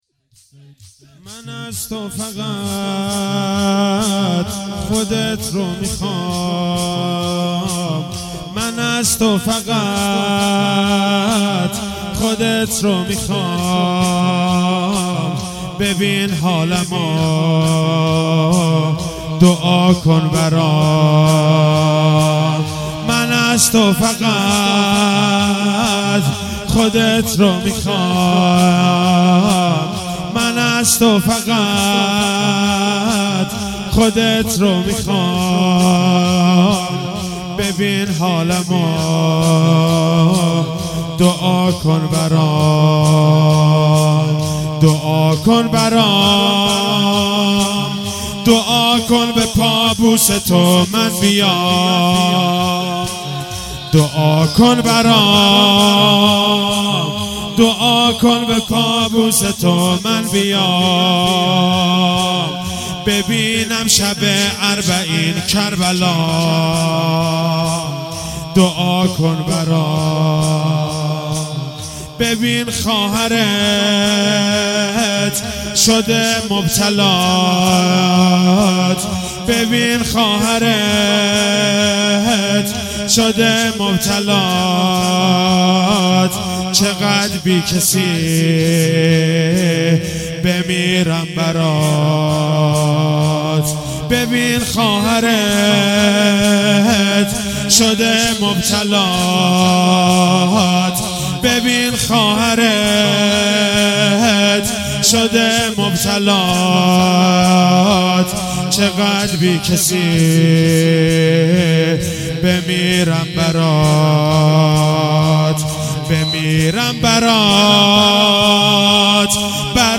شهادت حضرت ام البنین سلام الله علیها1438
شور مداحی